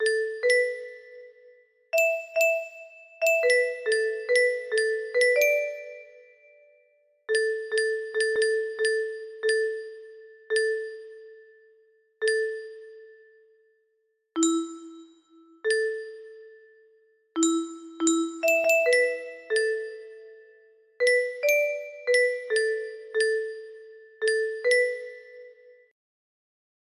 music boxes